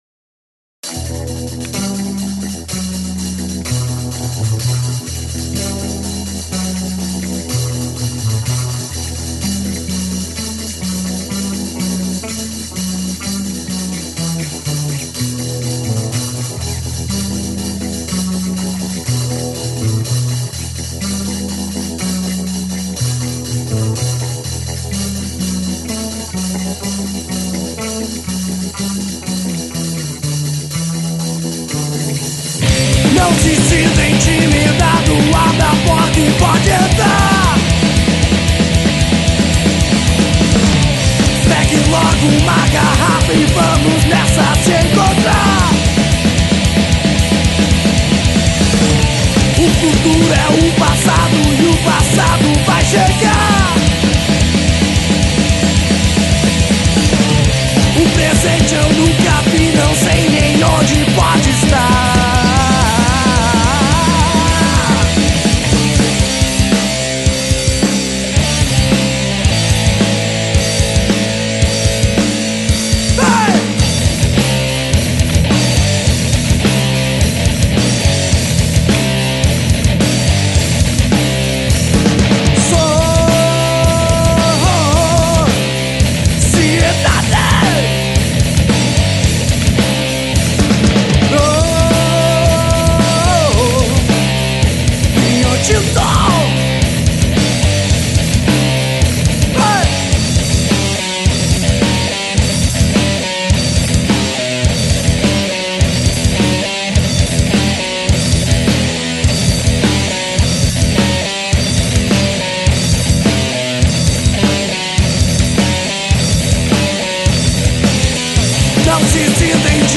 EstiloRock